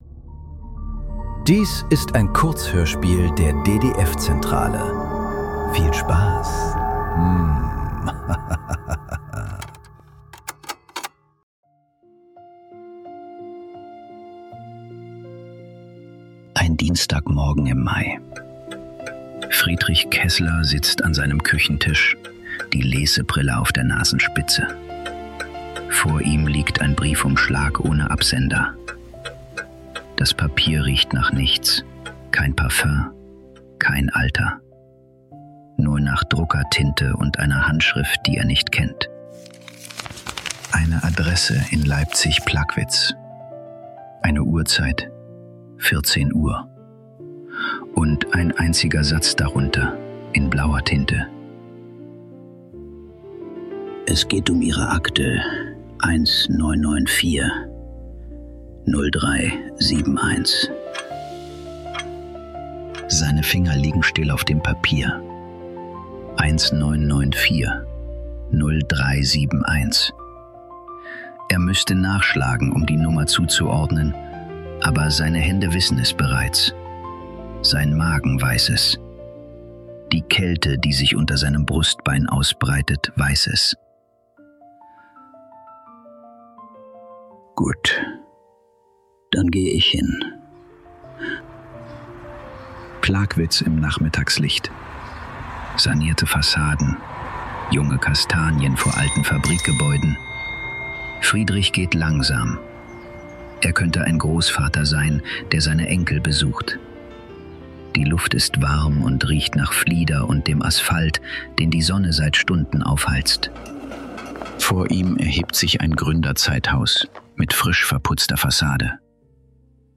Kurzhörspiele.